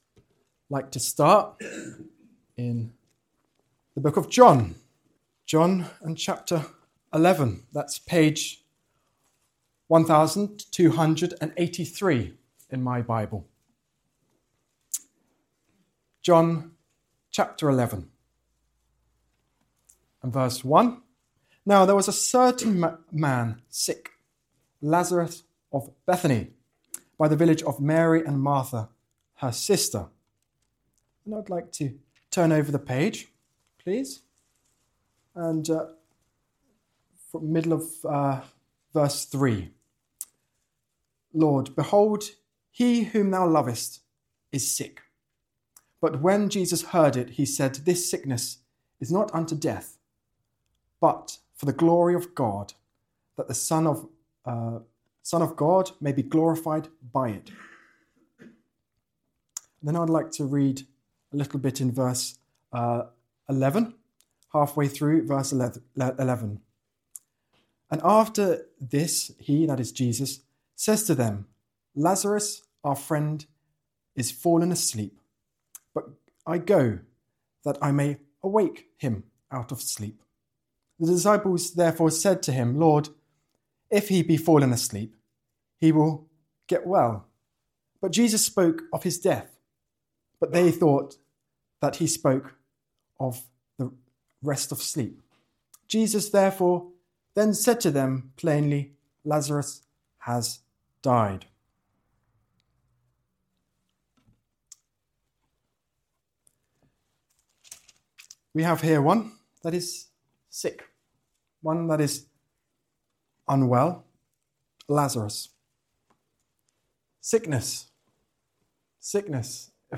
In this message, we see that no situation is too far gone, no life too broken, and no hope too buried for the voice of Christ. When Jesus calls, death must respond, and what was bound must be loosed. This preaching will stir your faith and remind you that God can bring life out of any grave.